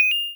coin.wav